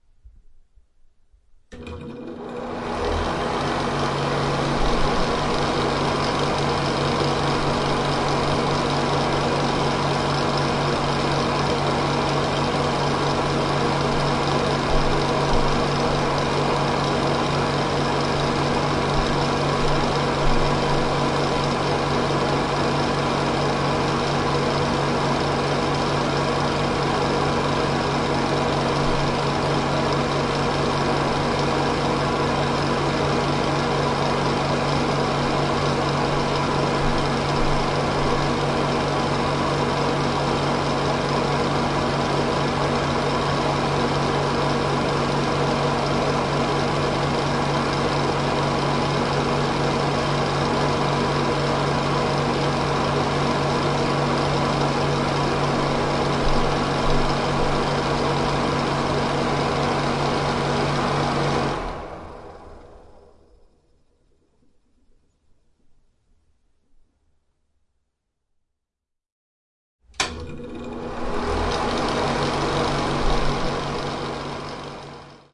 随机" 风扇嘎嘎作响 卫生间关闭 开始，停止 +开关结束 麦克风120度 1
描述：风扇rattly浴室关闭启动，停止+开关结束麦克风120度1.flac
标签： 风扇 关闭 rattly 开关 启动 停止 浴室
声道立体声